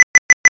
Radar Warning Receiver
CARAPACE_4Beeps.ogg.mp3